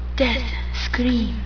Sense we haven't started the dub yet I put up audition clips.